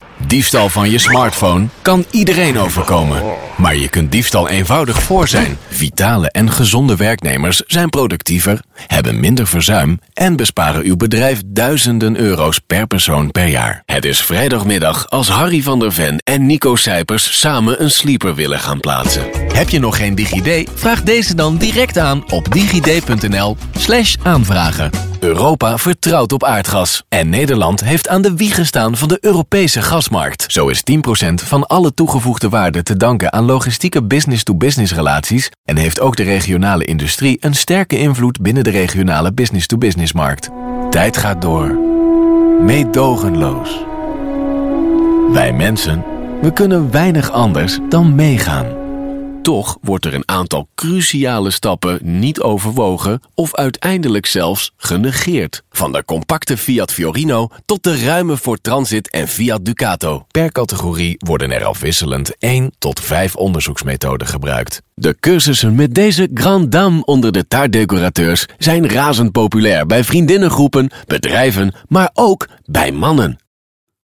NL HS EL 01 eLearning/Training Male Dutch